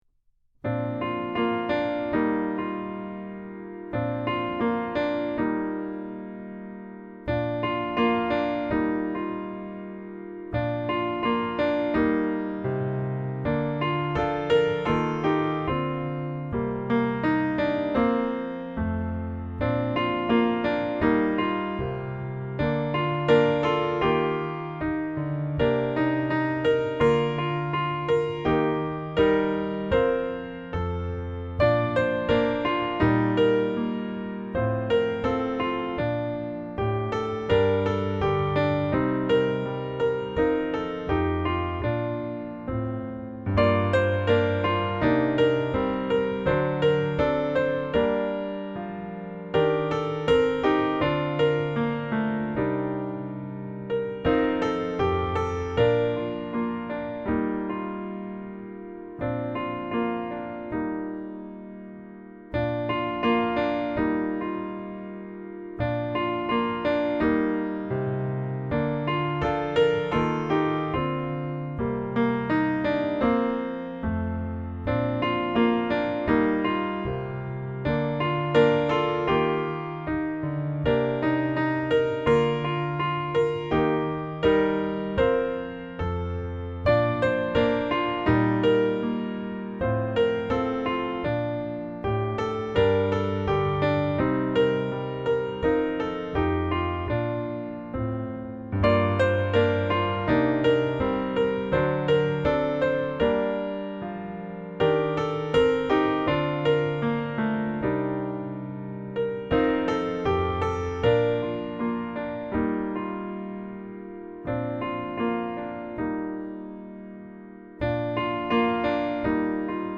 أسير ويسوع (MP3 audio (accompaniment))